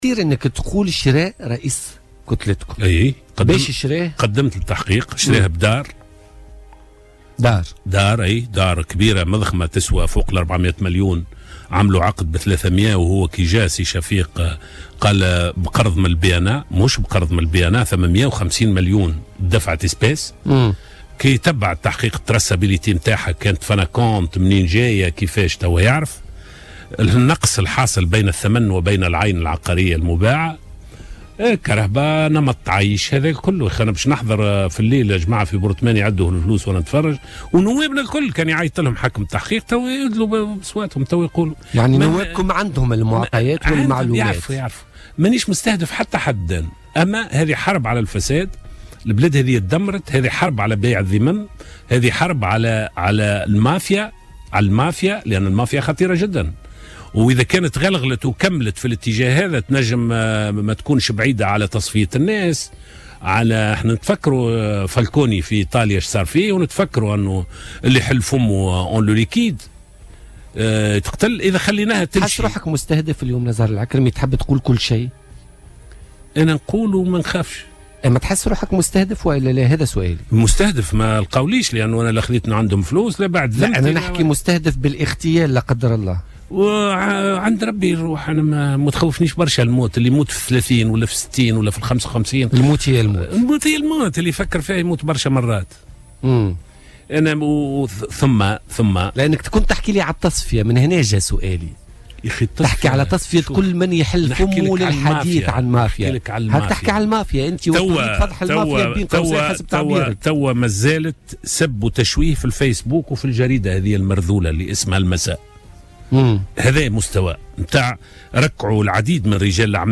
واكد ضيف "بوليتيكا" على "الجوهرة أف أم" هذه التهمة التي أصبح ملفها بين "أيدي القضاء".